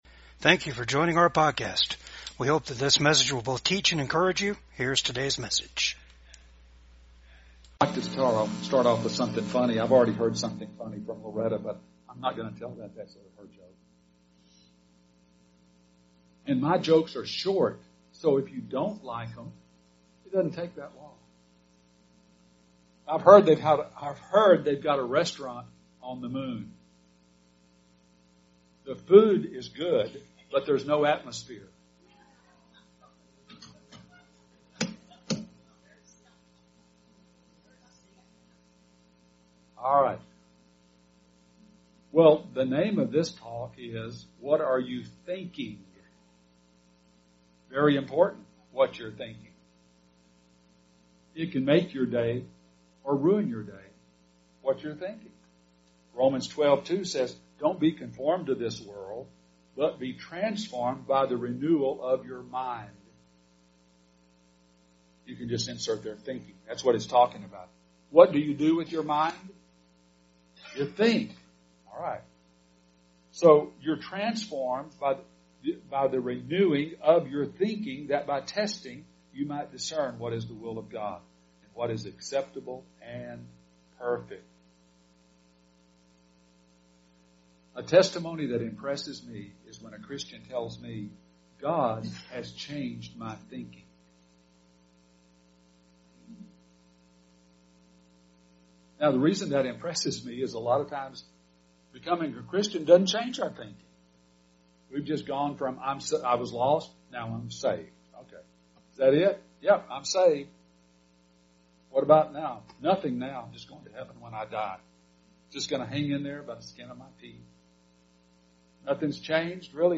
Romans 12:2 Service Type: VCAG WEDNESDAY SERVICE Get your thoughts synced up with God’s thoughts.